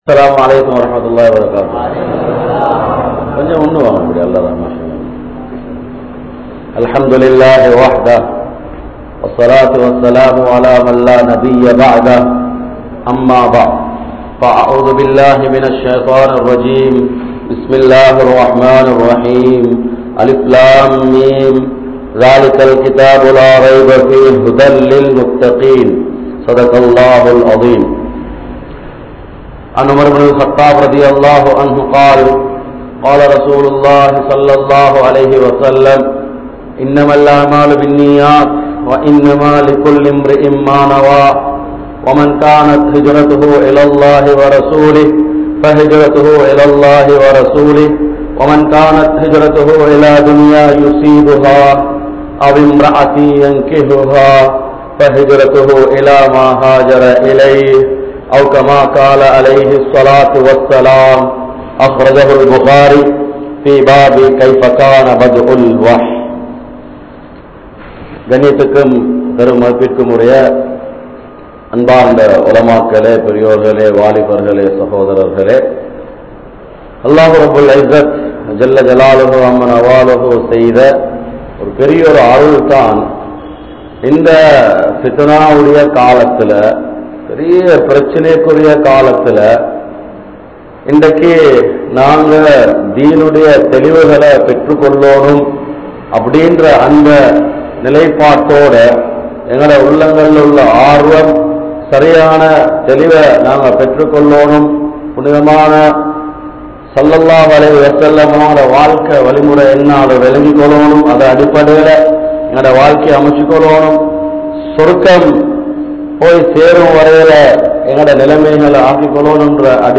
Ethirhaala Thalaivarhal (எதிர்காலத் தலைவர்கள்) | Audio Bayans | All Ceylon Muslim Youth Community | Addalaichenai